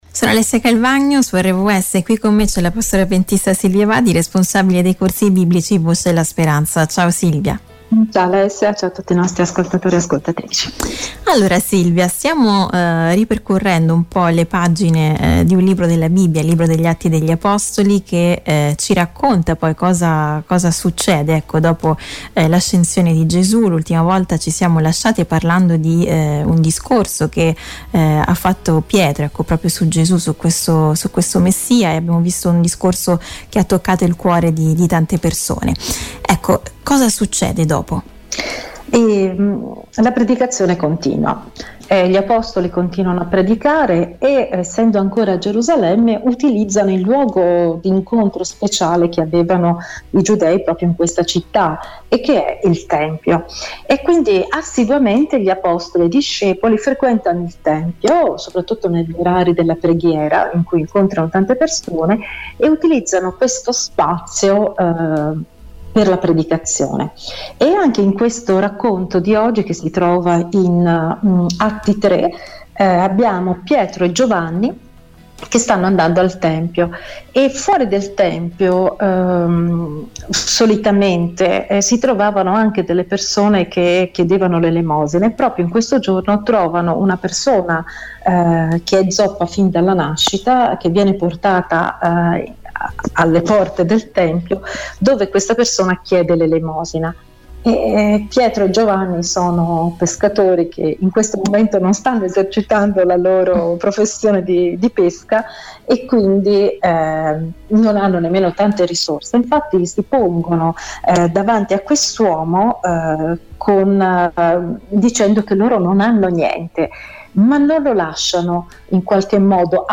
Un miracolo straordinario, che ancora oggi ci insegna qualcosa di molto importante. Intervista